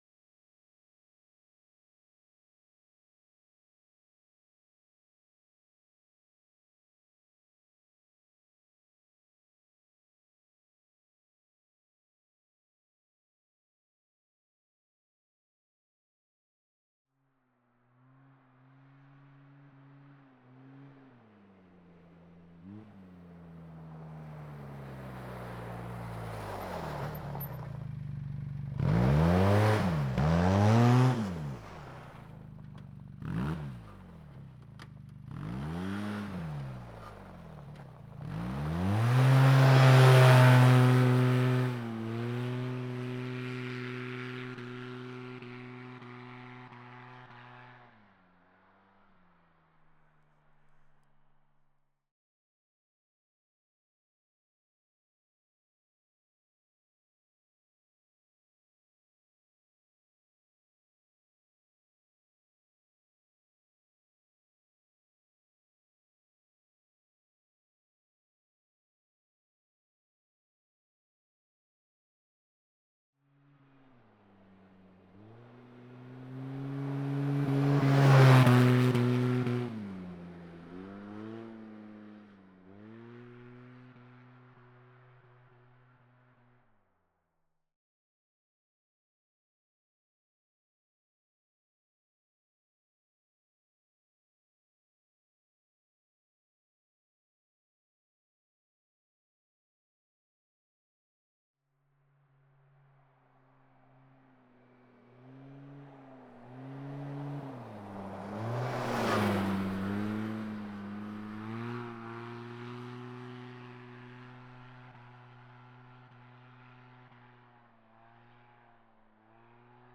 Textron Wildcat Sport - t3 - EXT - FAST - Up Stop Reverse Away By x2 - CSS5.wav